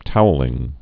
(touə-lĭng, toulĭng)